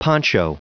Prononciation du mot poncho en anglais (fichier audio)
Prononciation du mot : poncho